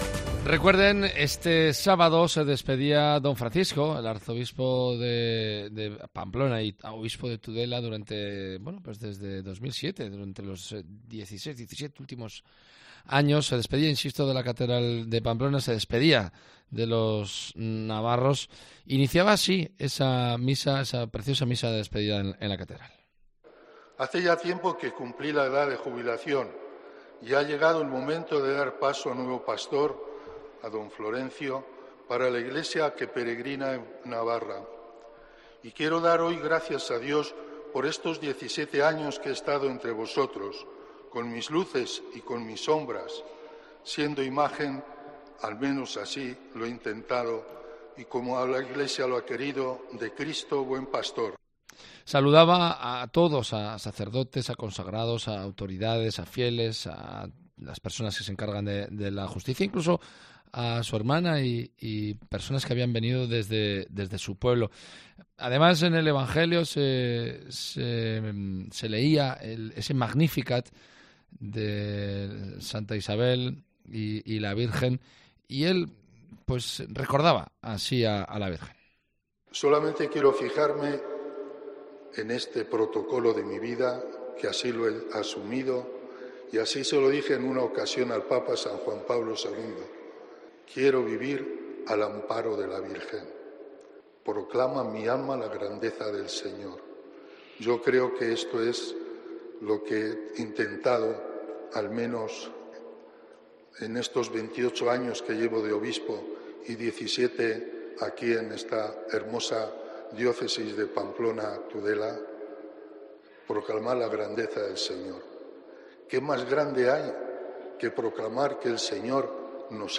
Don Francisco Pérez se despidió el sábado de Pamplona en una solemne misa en la Catedral de Pamplona
Don Francisco, en la homilía, pidió perdón a quienes se hayan sentido ofendidos, pidió por la paz, dio las gracias, solicitó amor y se acordó de todos en una sentida despedida del pueblo navarro.